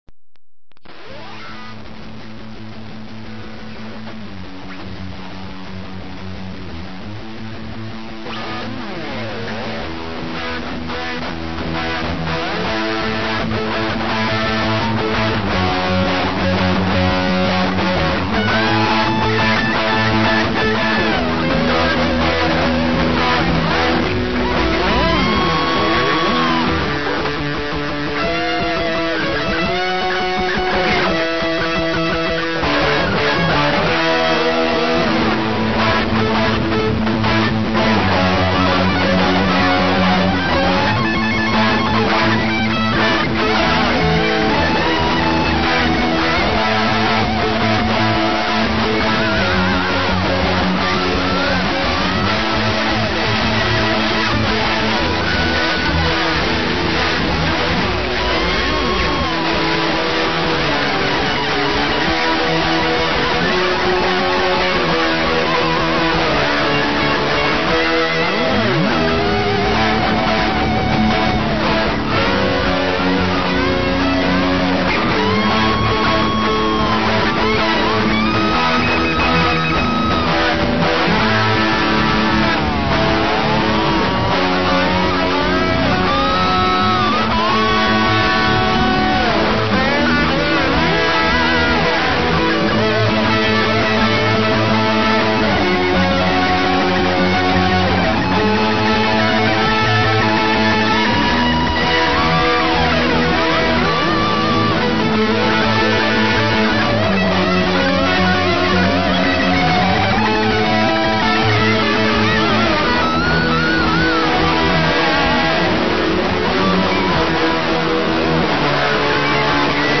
はっきり言って雑音です。 (MP3､完成度は無茶苦茶低いです)